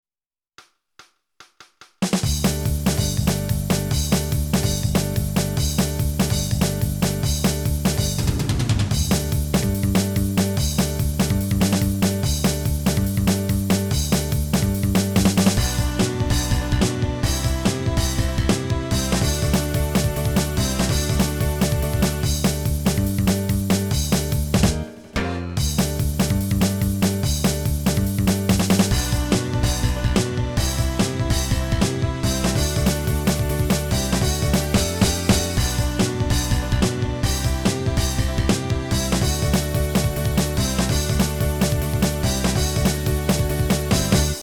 music (MIDI to MP3)